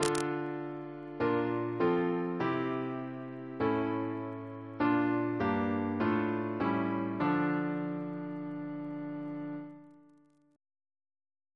Single chant in D minor Composer: William Crotch (1775-1847), First Principal of the Royal Academy of Music Reference psalters: H1940: 618; PP/SNCB: 43